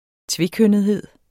Udtale [ ˈtveˌkœnˀəðˌheðˀ ]